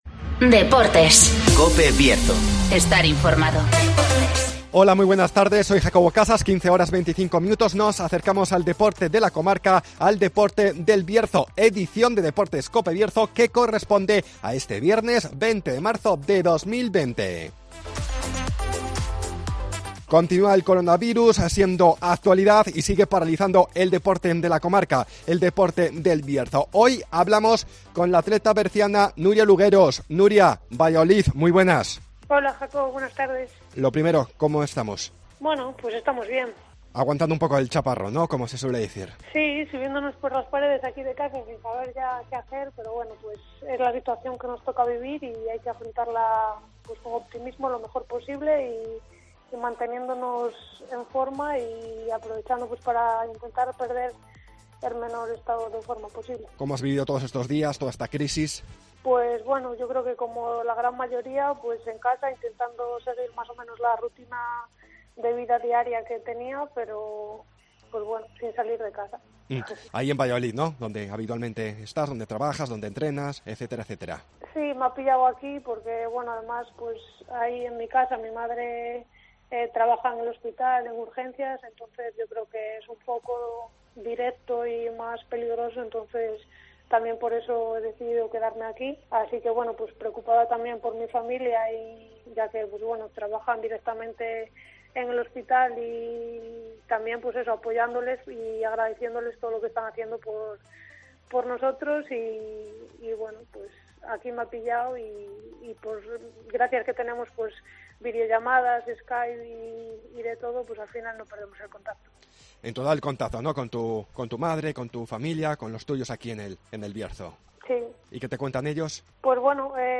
-Crisis del coronavirus en el deporte berciano -Entrevista